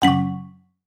CommandWrong.wav